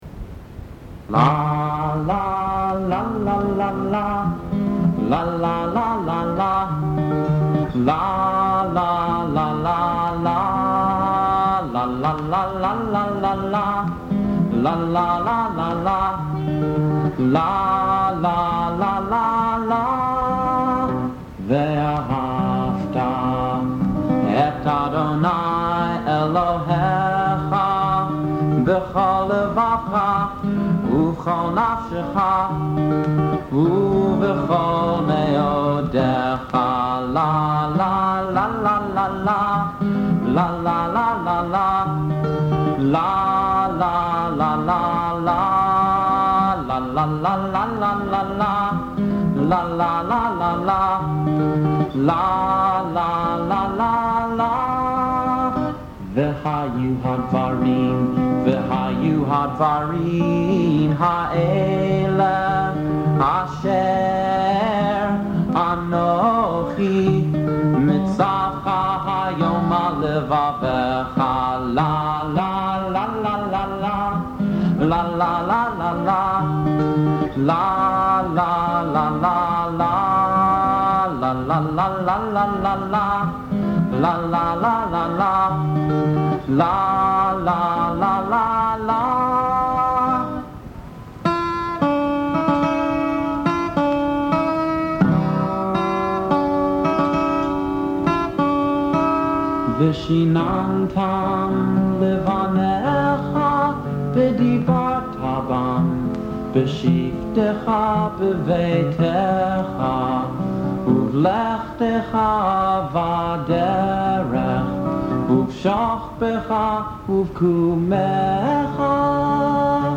“V’ahavta” from Junior Choir Songs for the High Holy Days.